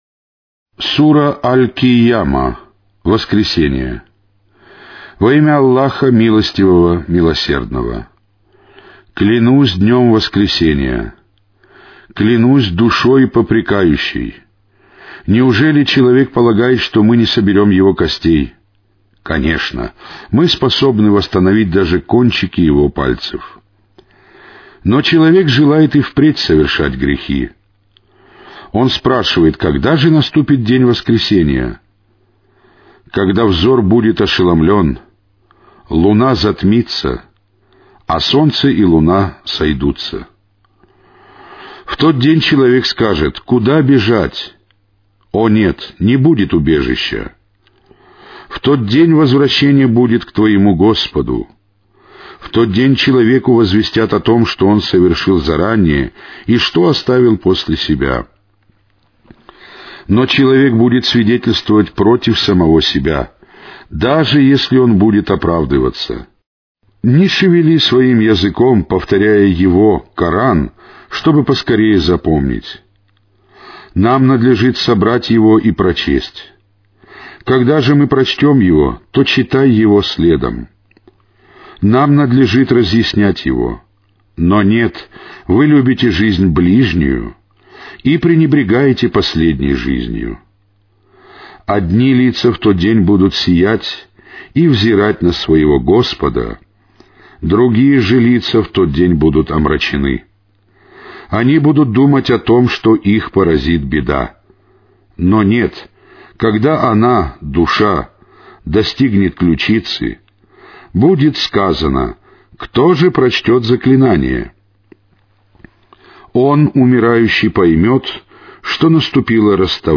Каналы 1 (Mono).
Аудиокнига: Священный Коран